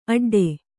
♪ aḍḍe